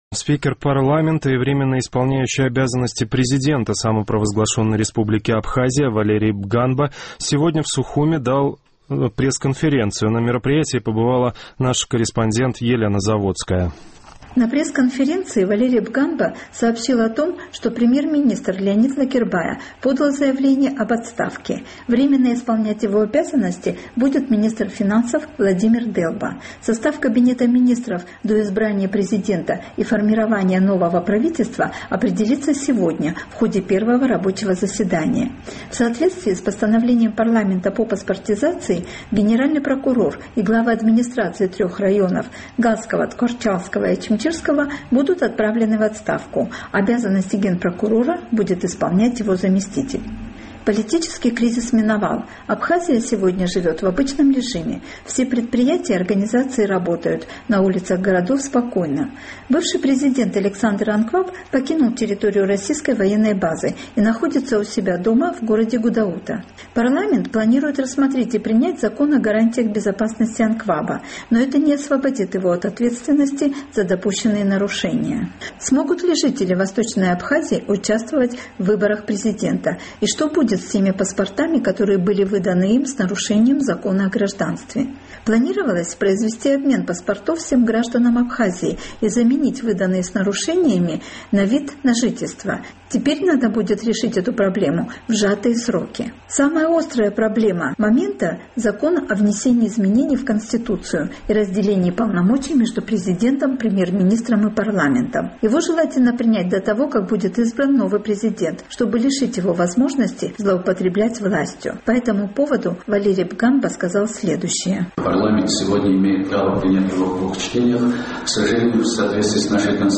Спикер и временно исполняющий обязанности президента Абхазии Валерий Бганба сегодня в Сухуме на пресс-конференции в парламенте ответил на вопросы журналистов.